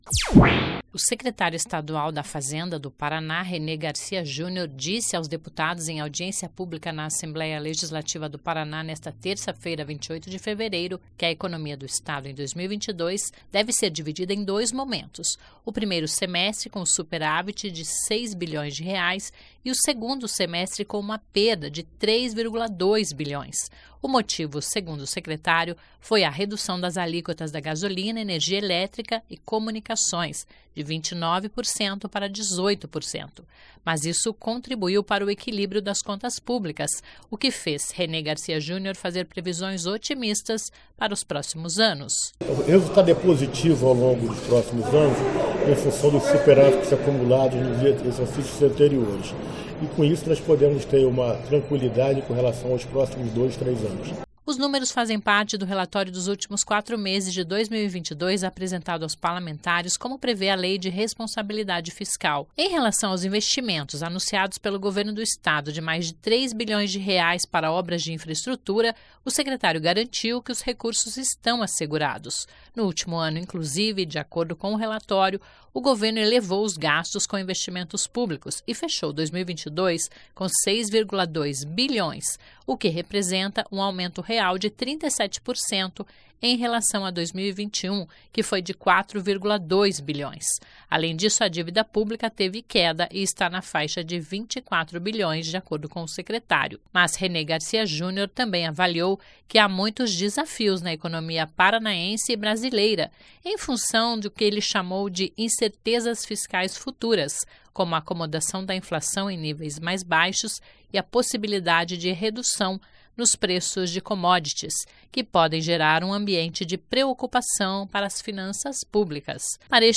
O secretário estadual da Fazenda do Paraná, René Garcia Júnior, disse aos deputados, em audiência pública na Assembleia Legislativa do Paraná nesta terça-feira (28),  que a economia do estado em 2022 deve ser dividida em dois momentos: o primeiro semestre, com  um superávit de R$ 6 bilhões de reais  e o segundo semestre, com uma perda de R$ 3,2 bilhões.
(Sonora)